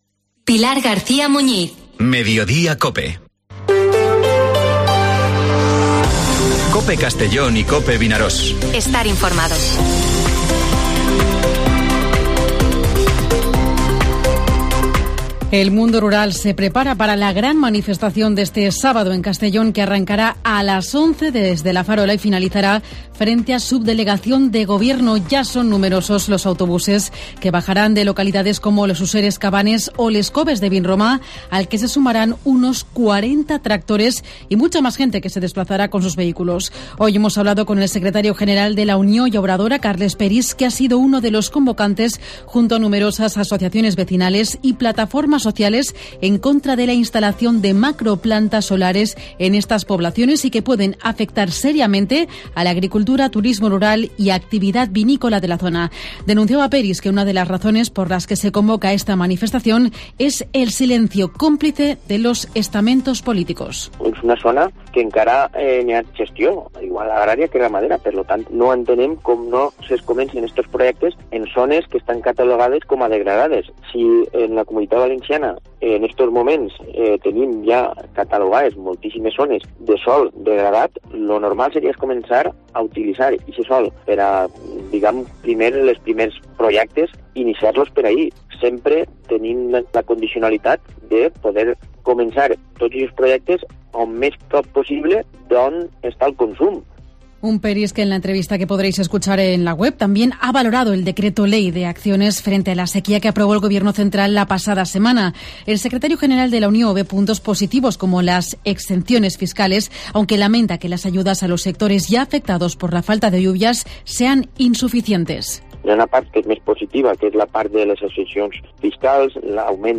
Informativo Mediodía COPE en la provincia de Castellón (17/05/2023)